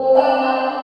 64 GUIT 6 -L.wav